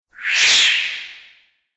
character_disappear.ogg